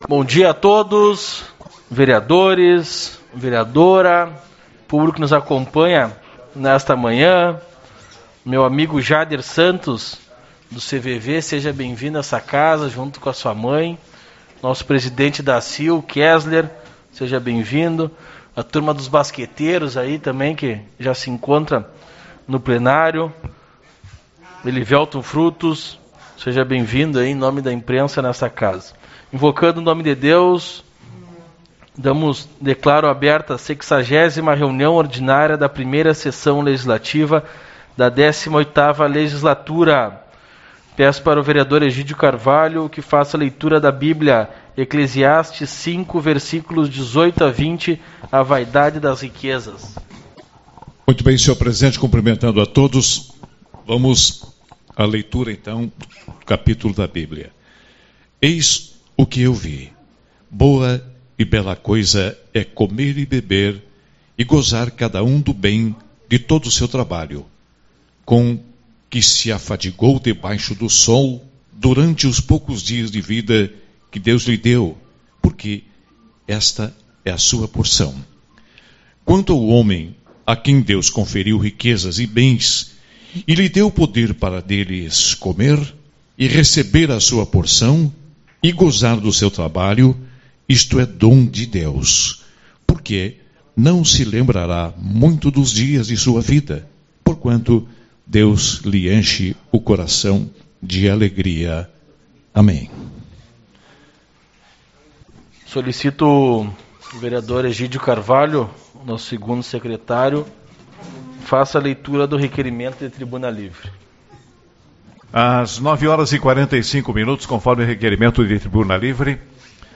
Parte 1-Trib.Livre-Setembro Amarelo-CVV — Câmara Municipal de Uruguaiana